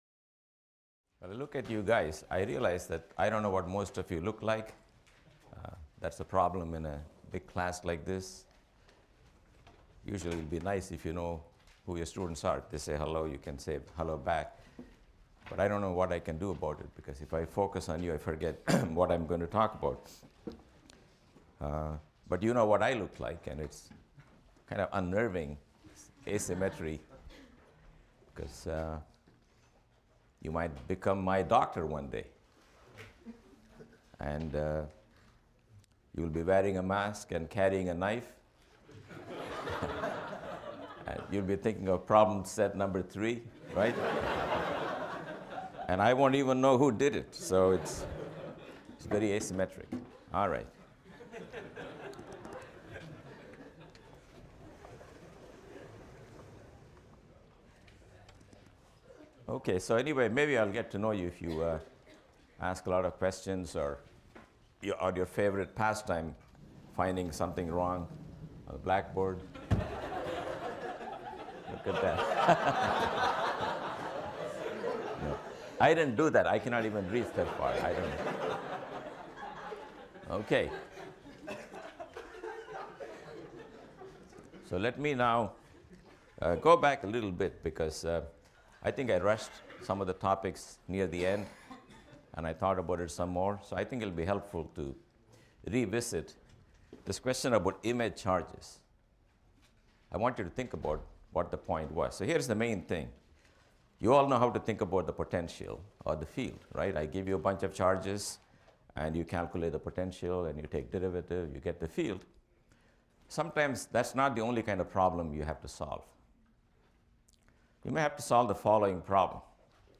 PHYS 201 - Lecture 7 - Resistance | Open Yale Courses